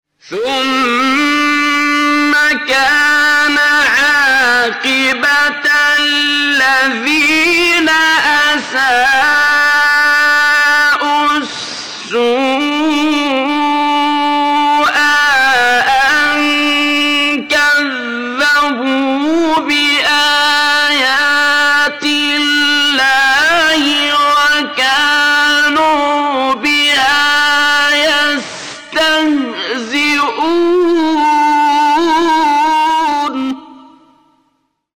صوت | تلاوت آیه 10«سوره روم» با صوت قاریان شهیر
تلاوت آیه 10 سوره روم با صوت ابوالوفا صعیدی